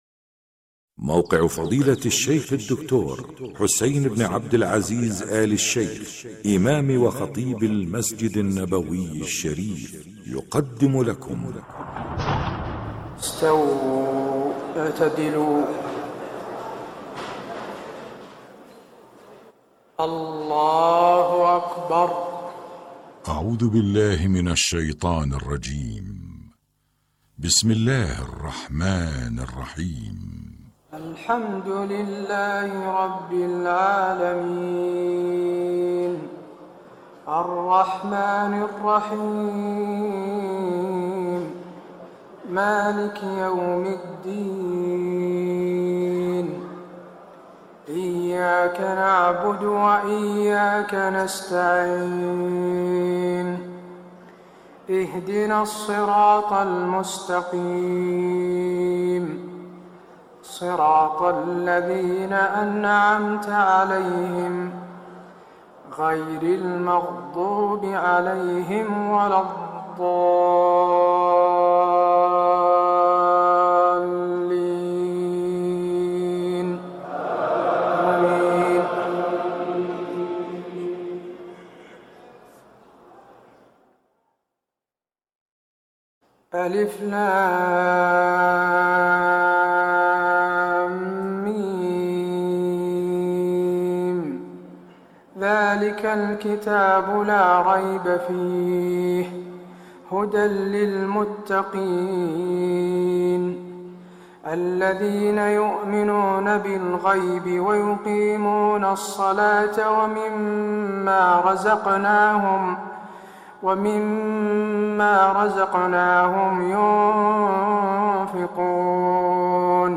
تهجد ليلة 21 رمضان 1425هـ من سورة البقرة (1-43) Tahajjud 21 st night Ramadan 1425H from Surah Al-Baqara > تراويح الحرم النبوي عام 1425 🕌 > التراويح - تلاوات الحرمين